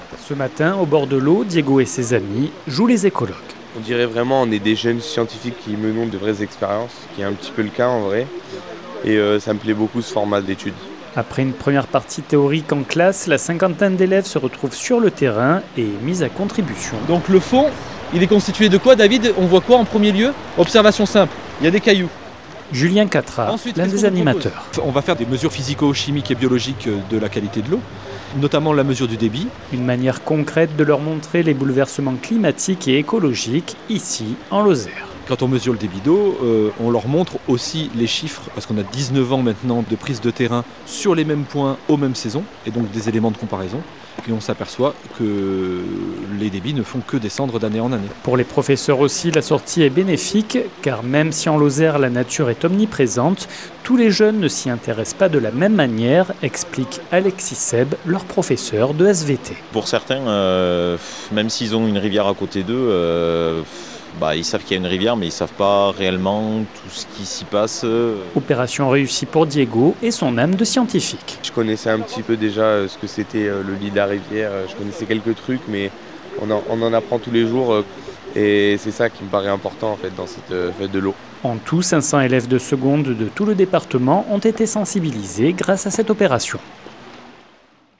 Dans ce cadre, les lycéens sont invités à se rendre sur le terrain pour effectuer des relevés sur leur rivière de proximité. 48 FM s’est rendu sur les berges du Lot avec des élèves du lycée Chaptal de Mende.
Reportage